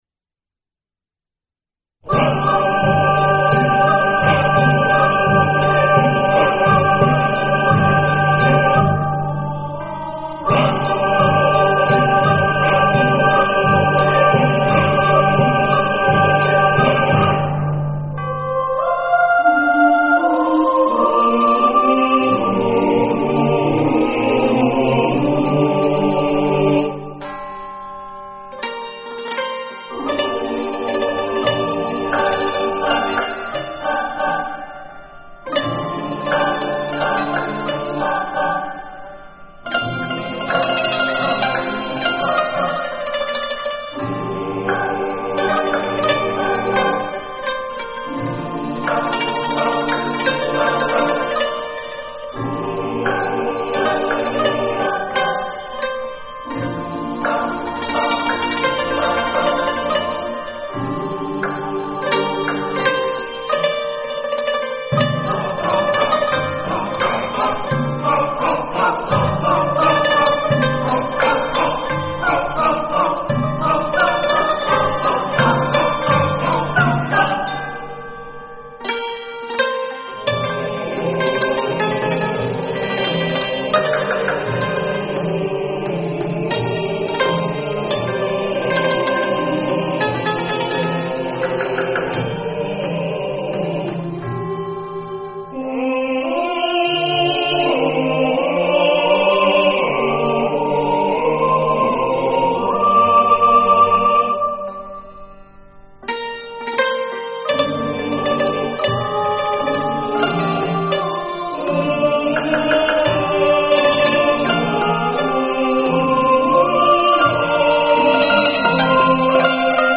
تک آهنگ بی کلام
برای گروه کُر، پرکاشن و سنتور
سنتور
گروه کرِ چهارصدایی